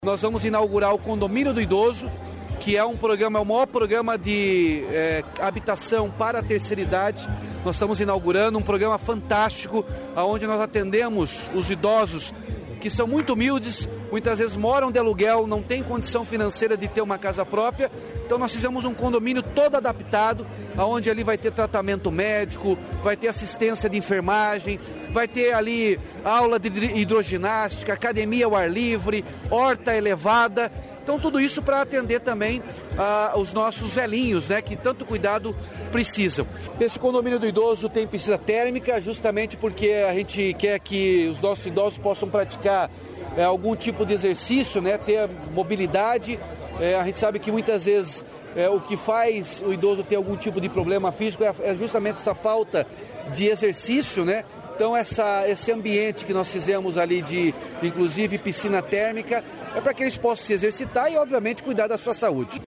Sonora do governador Ratinho Junior sobre condomínio do idoso em Ponta Grossa